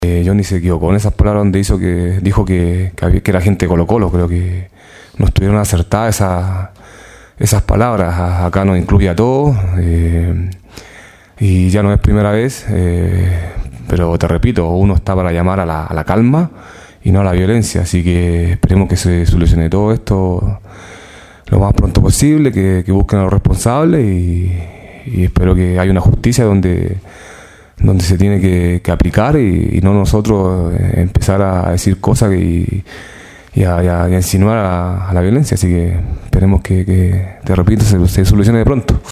Lo ocurrido en las inmediaciones del Centro Deportivo Azul no dejó indiferente al goleador albo, quien señaló en rueda de prensa en el Monumental que “fue lamentable lo que sucedió ayer en el CDA, por la integridad de los jugadores, las familias, los niños. Somos personas públicas y tenemos que llamar a la calma”.